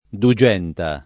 Dugenta [ du J$ nta ]